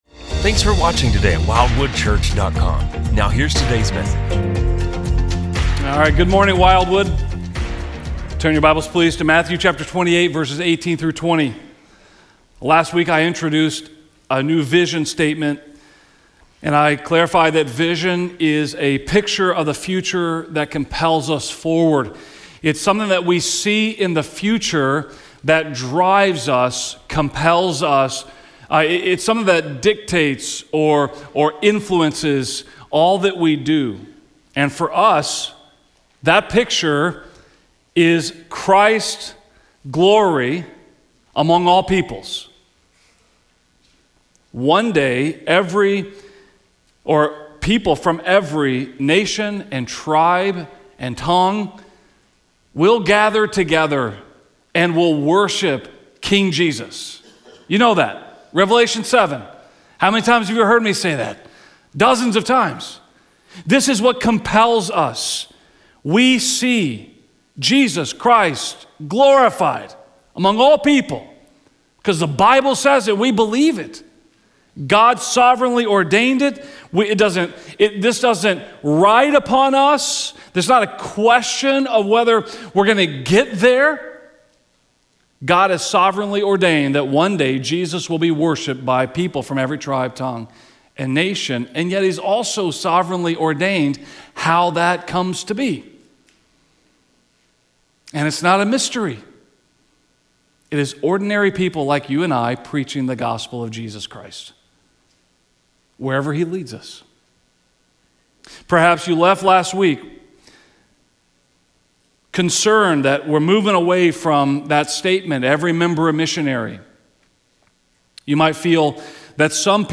This sermon invites our church family to unite around a vision that has compelled God’s people for millennia and will culminate in the worship of Christ by all peoples.